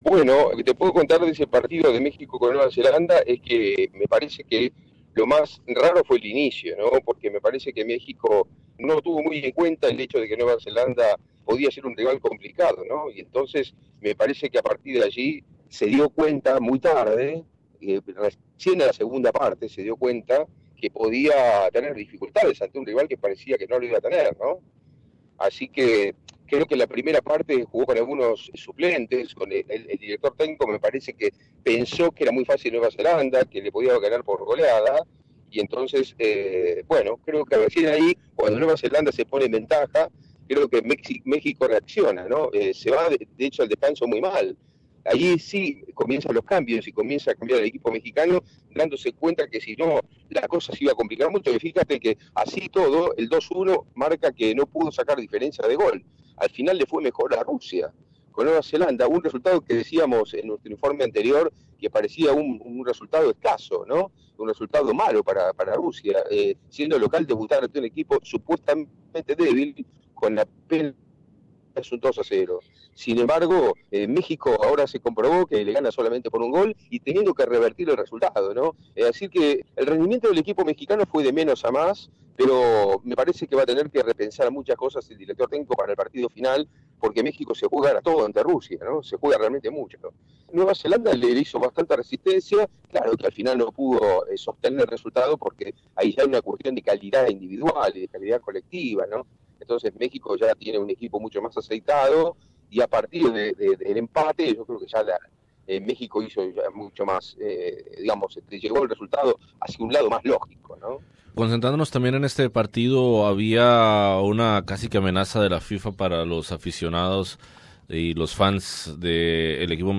Escucha el análisis de la jornada